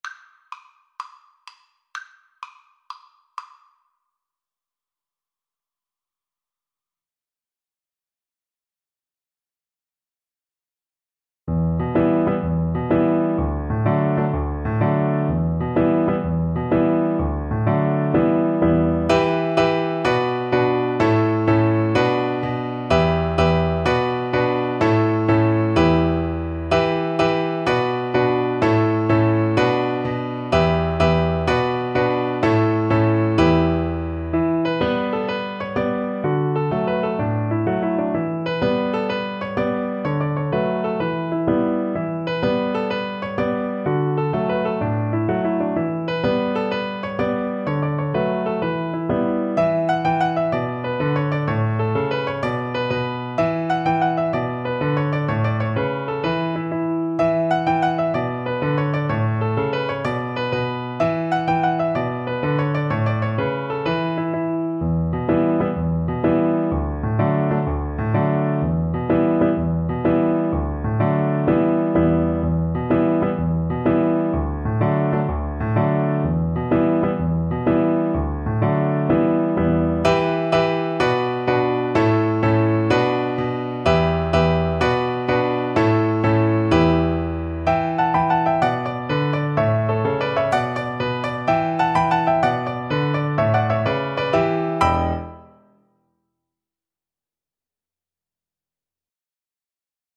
12/8 (View more 12/8 Music)
Fast .=c.126
Irish